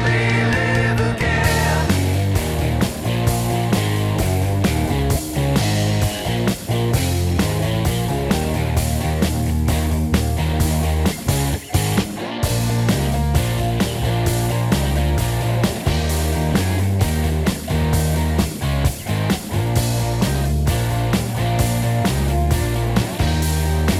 Down One Semitone Rock 4:23 Buy £1.50